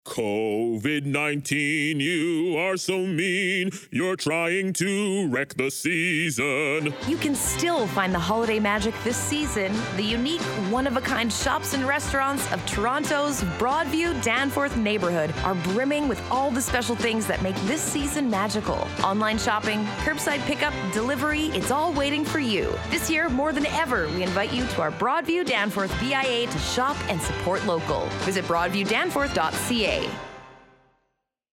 In addition to the certificate gifting program, we have created a radio commercial promoting shopping in our BIA.